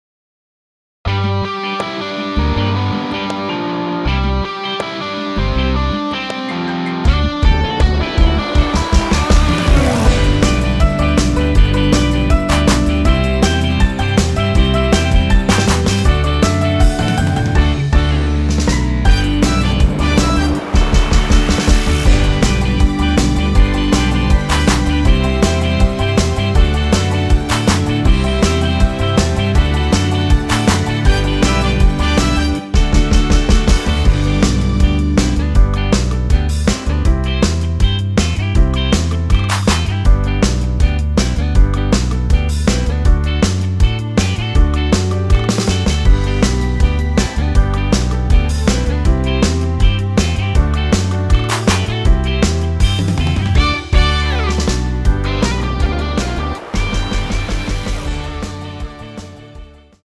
(+2) 올린 MR 입니다.(미리듣기 참조)
음정은 반음정씩 변하게 되며 노래방도 마찬가지로 반음정씩 변하게 됩니다.
앞부분30초, 뒷부분30초씩 편집해서 올려 드리고 있습니다.